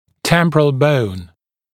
[‘tempərəl bəun][‘тэмпэрэл боун]височная кость